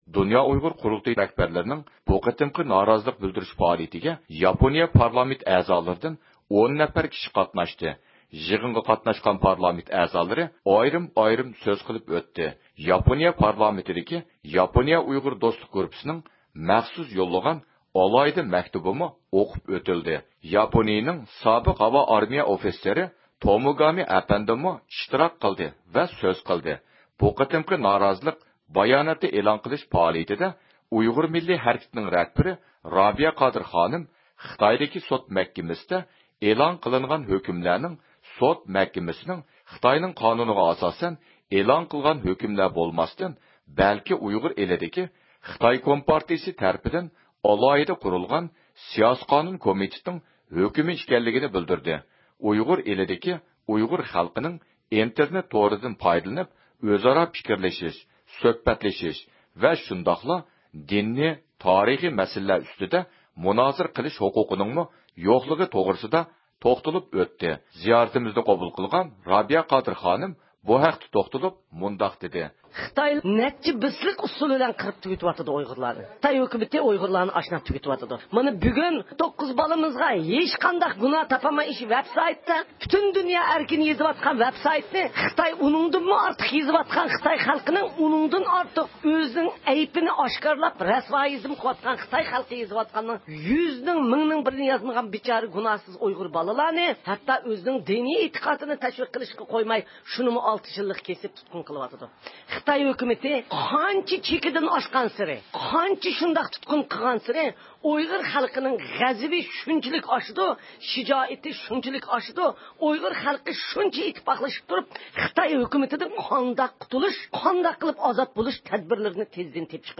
د ئۇ ق رەھبەرلىرى توكيودىكى سېىريو سارىيىدا قەشقەر ۋە ئاقسۇ سوت مەھكىمىسىنىڭ ھۆكۈمىگە نارازىلىق بىلدۈرۈپ بايانات ئېلان قىلدى. 2013-يىلى 21-ئىيۇن، ياپونىيە.
زىيارىتىمىزنى قوبۇل قىلغان رابىيە قادىر خانىم بۇ ھەقتە توختالدى.